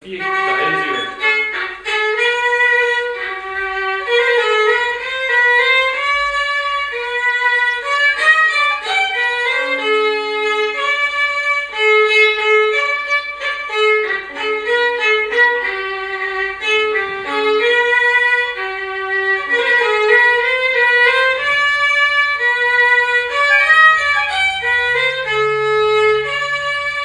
danse : valse
répertoire musical au violon
Pièce musicale inédite